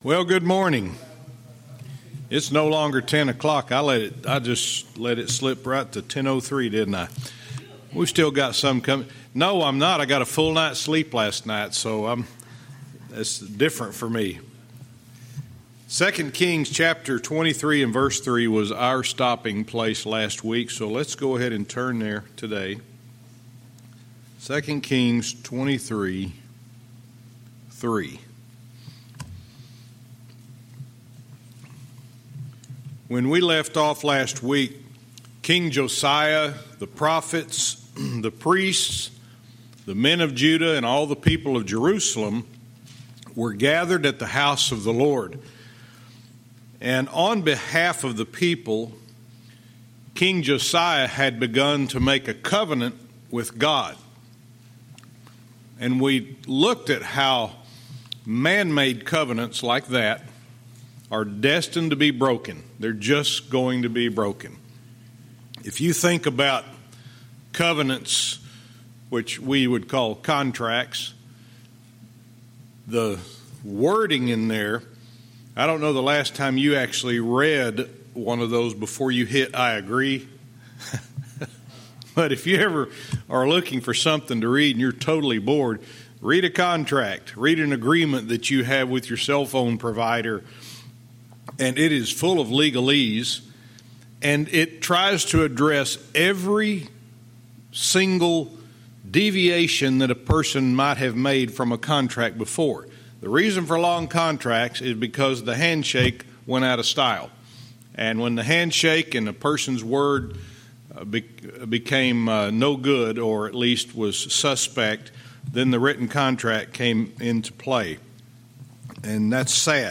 Verse by verse teaching - 2 Kings 23:3-4